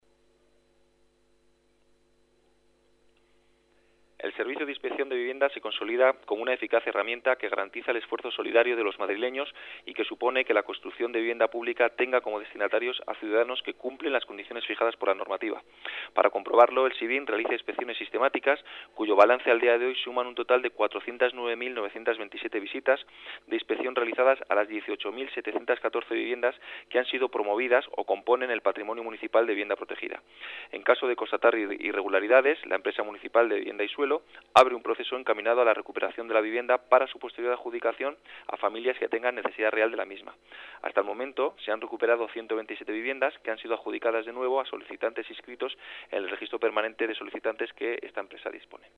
Nueva ventana:Declaraciones de Pablo Olangua, director general de Gestión de la EMVS sobre inspección de viviendas